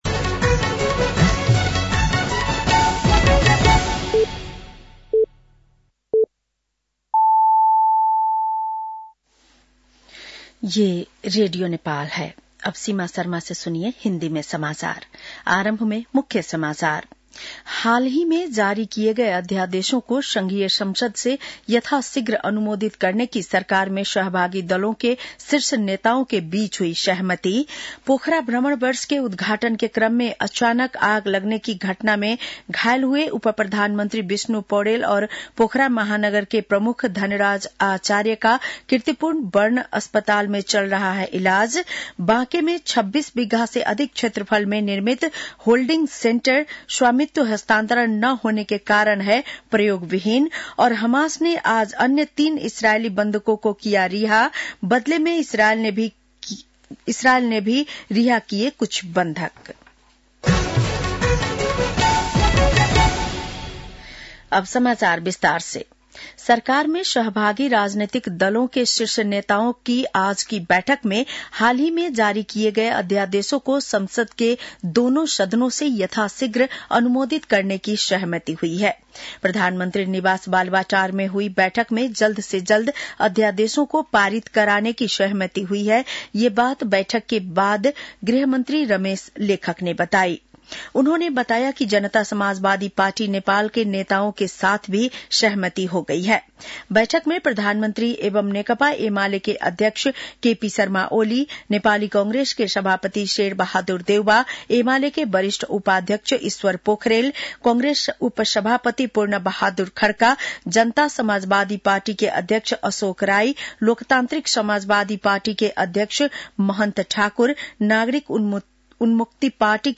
बेलुकी १० बजेको हिन्दी समाचार : ४ फागुन , २०८१
10-pm-news.mp3